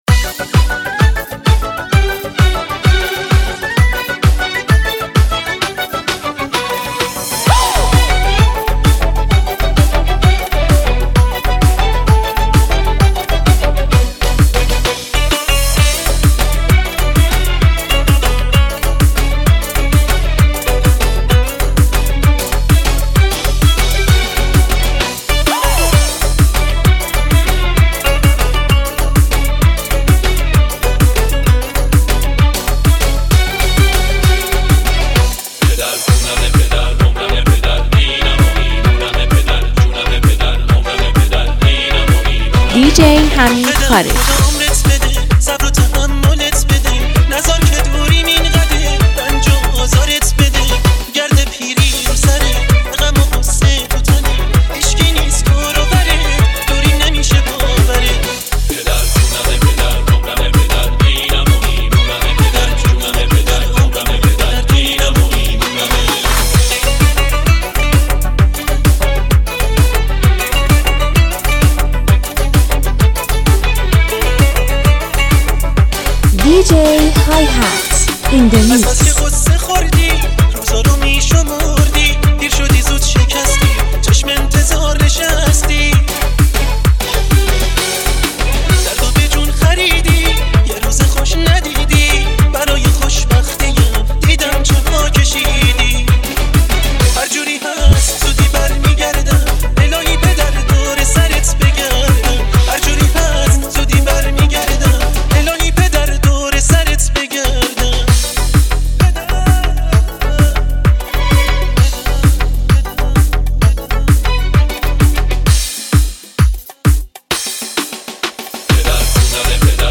ریمیکس نوستالژی و زیبا
با صدای گرم
پخش آنلاین موزیک ریمیکس روز مرد